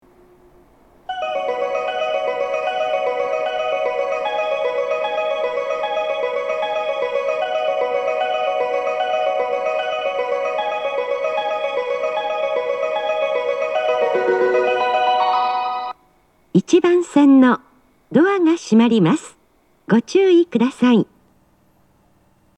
発車メロディーの音量は小さいです。
また、スピーカーが上下兼用なので、交換のある列車の場合、放送が被りやすいです。
発車メロディー
余韻切りです。